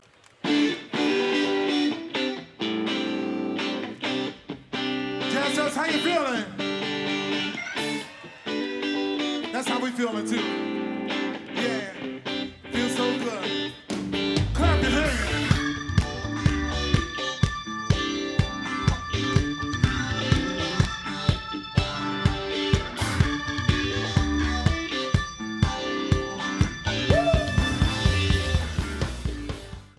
Jamband
R&B
Rock